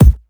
Tuned kick drum samples Free sound effects and audio clips
• Thick Kick Sample C Key 747.wav
Royality free bass drum single hit tuned to the C note. Loudest frequency: 408Hz
thick-kick-sample-c-key-747-az9.wav